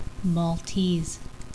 Pronunciation
Mall-TESE
Maltese.wav